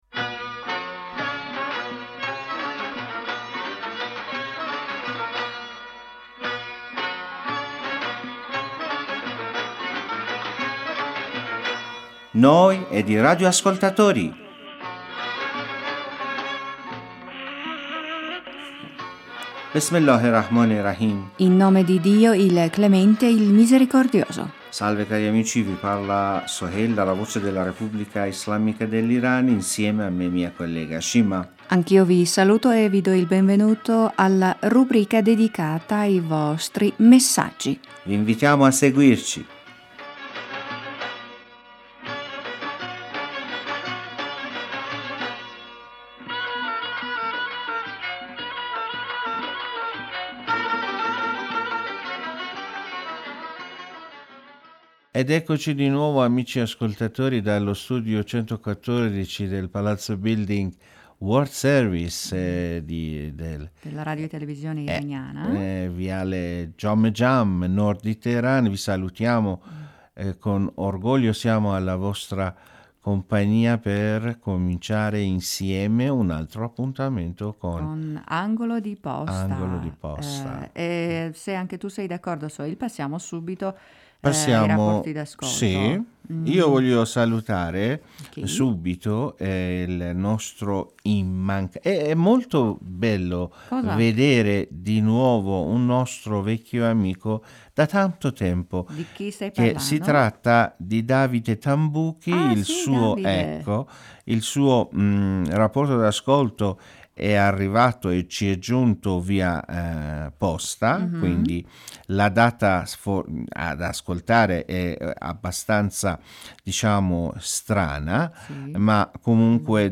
In questo programma in nostri speaker leggeranno i vostri messaggi e commenti.